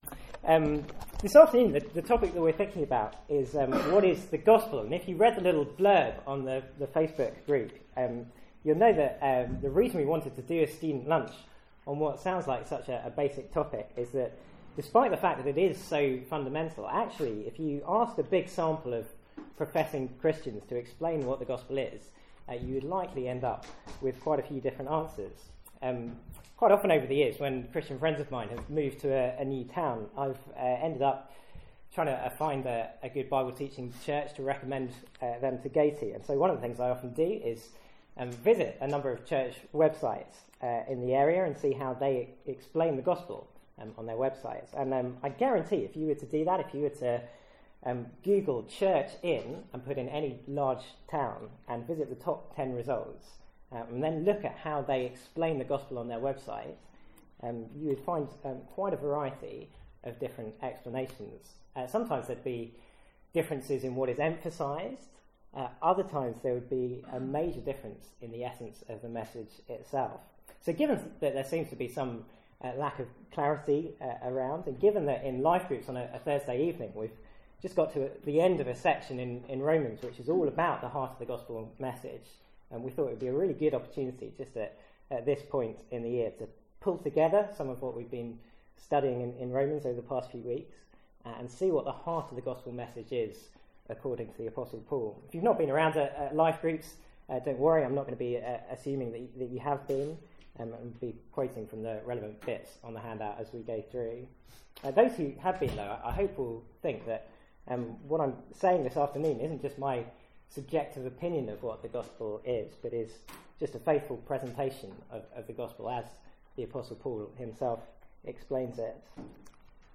From the student lunch on 1st November 2015.